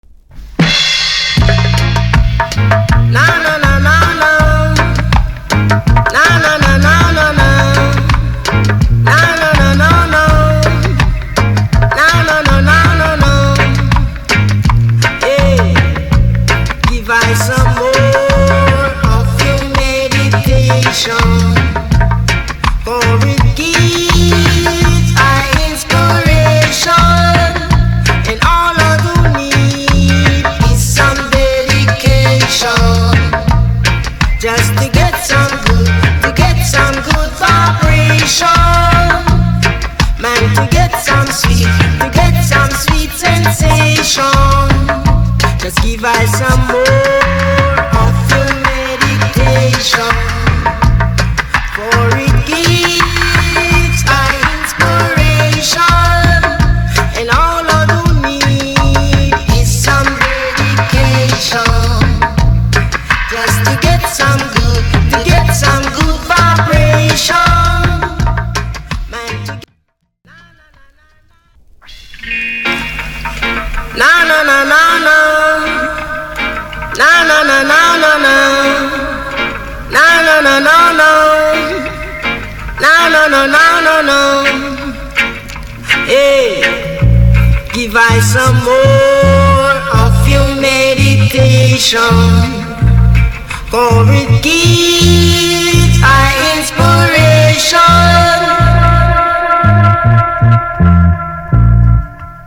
Proper roots reggae.
1977. 12 inch loud cut!